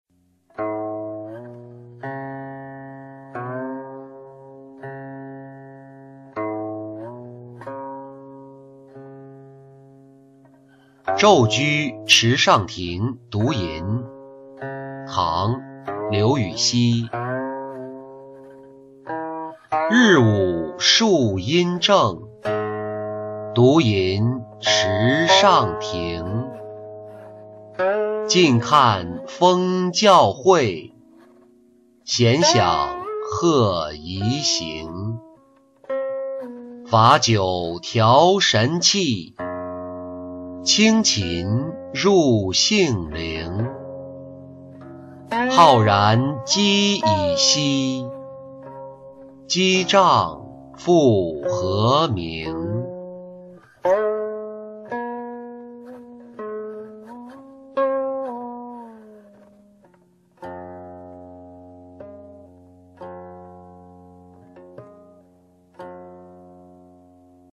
昼居池上亭独吟-音频朗读